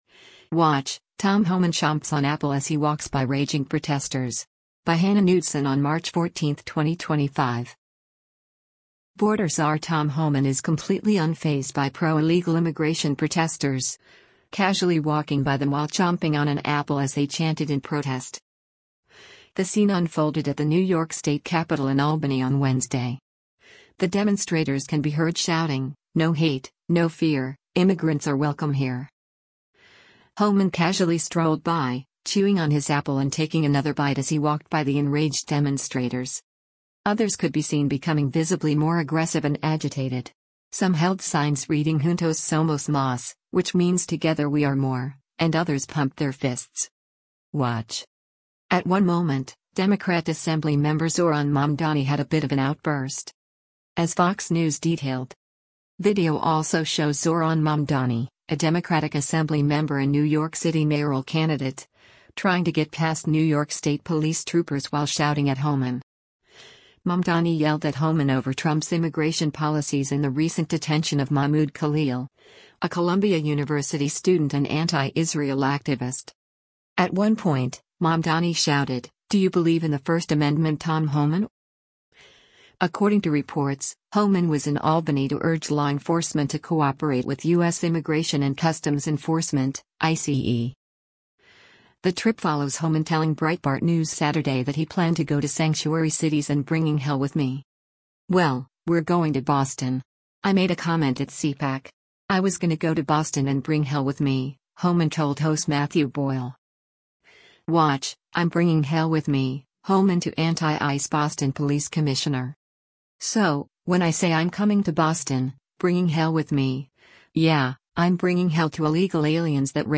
Border czar Tom Homan is completely unfazed by pro-illegal immigration protesters, casually walking by them while chomping on an apple as they chanted in protest.
The scene unfolded at the New York state Capitol in Albany on Wednesday. The demonstrators can be heard shouting, “No hate, no fear, immigrants are welcome here.”
Video also shows Zohran Mamdani, a Democratic Assembly member and New York City mayoral candidate, trying to get past New York State police troopers while shouting at Homan.